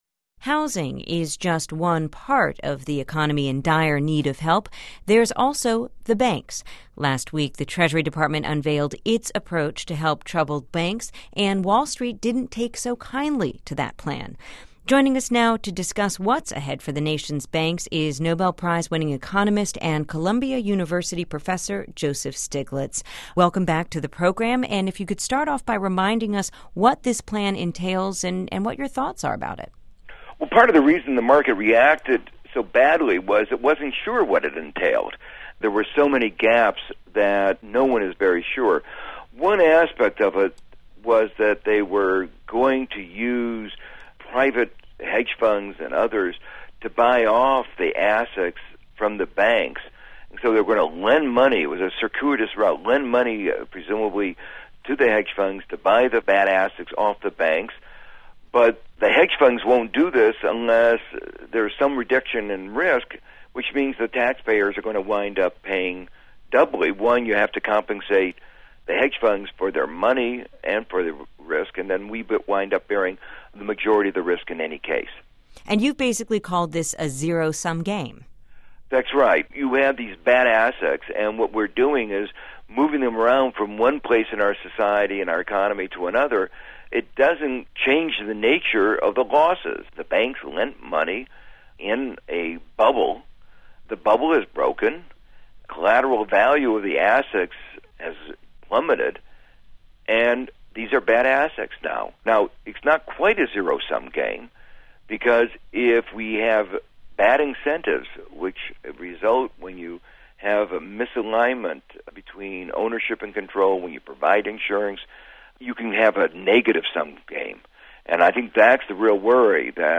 As regulators and policymakers confront the global economic meltdown, it seems as if shareholders and taxpayers are in warring camps. Nobel Prize-winning economist Joseph Stiglitz talks talks about the Obama administration's plan to bail out banks.